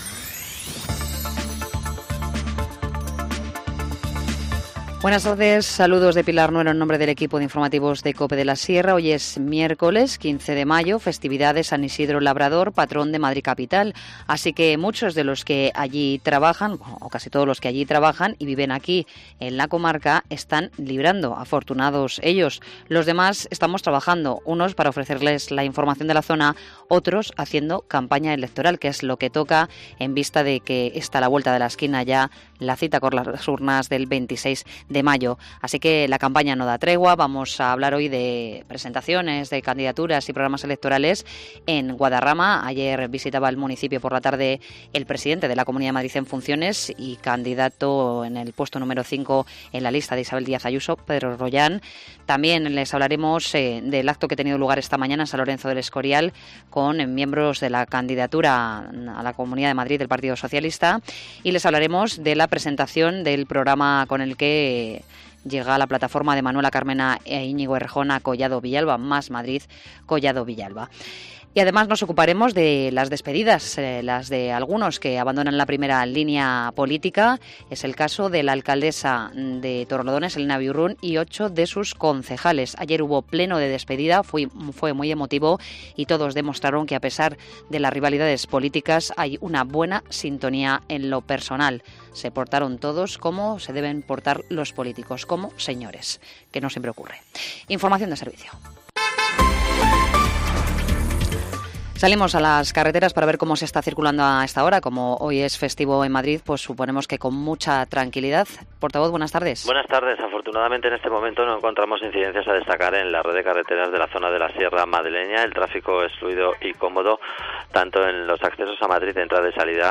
Informativo Mediodía 15 mayo 14:20h